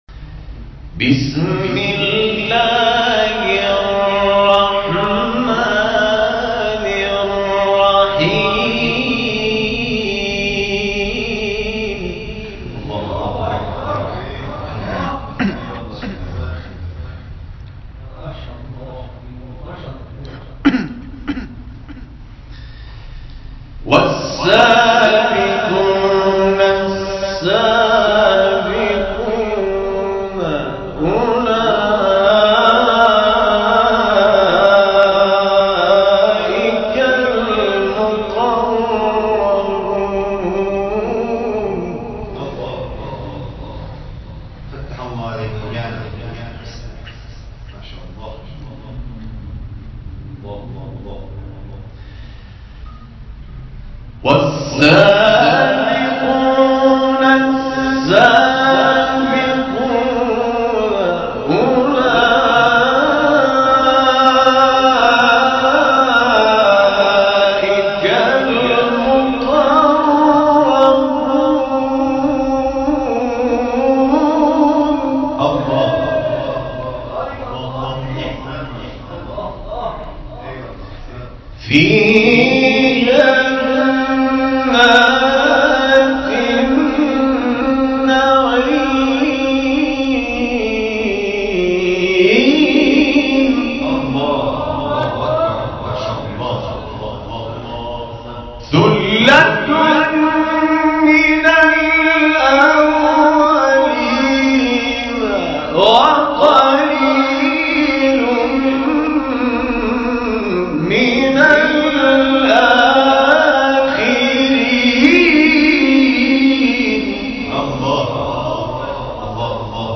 سپس با رسیدن به اوقات شرعی اذان مغرب و عشاء، نماز جماعت مغرب و عشاء برگزار شد و بعد از آن پذیرایی صورت گرفت.